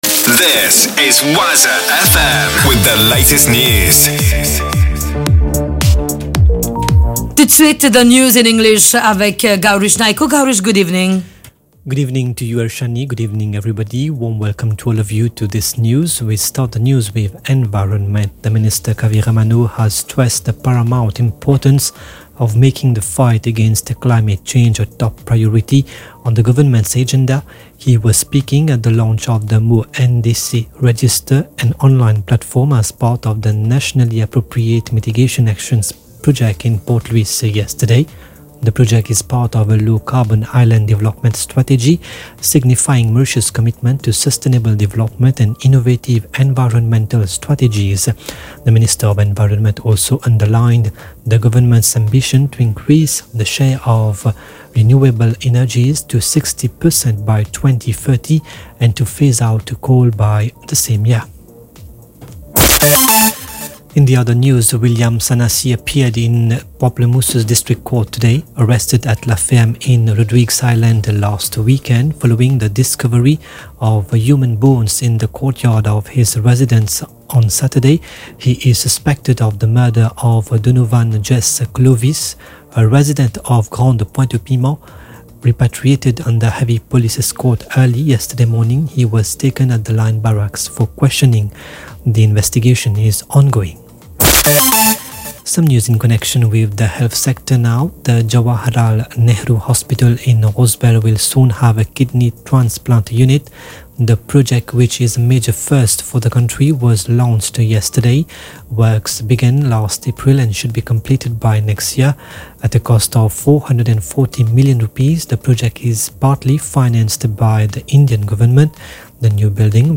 NEWS 18H - 24.11.23